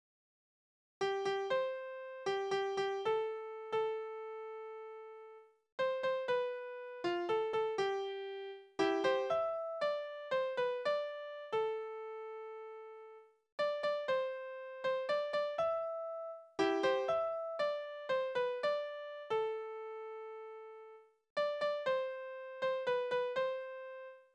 Lügenlieder: Verkehrte Welt
Tonart: C-Dur
Taktart: 3/4
Tonumfang: Oktave
Besetzung: vokal
Anmerkung: Takt 4 und 6 zweistimmig